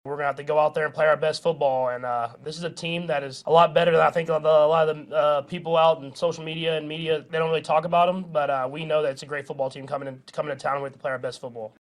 Chiefs quarterback Patrick Mahomes says that the Titans don’t get enough credit for being one of the best teams in the NFL.